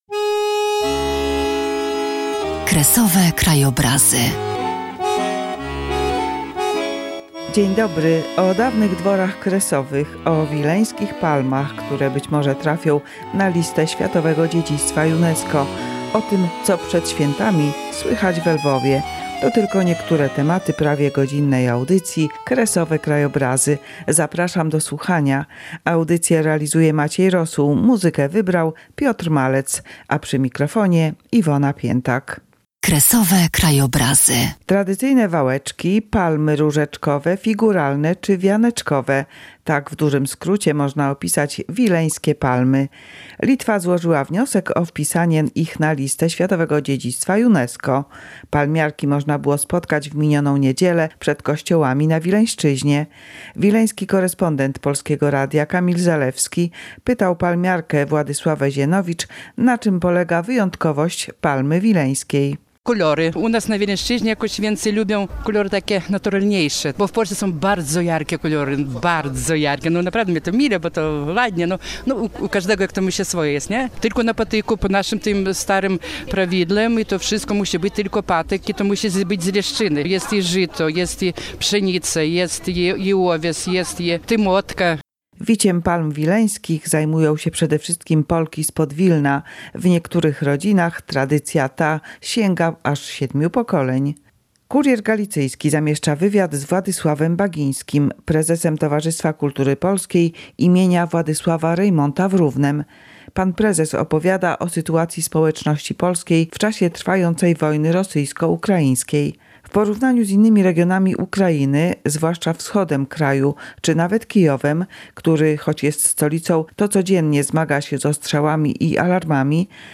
W tym wydaniu audycji Kresowe krajobrazy: aktualności z życia społeczności polskiej na Ukrainie i Litwie, rozmowa z organizatorami konferencji naukowej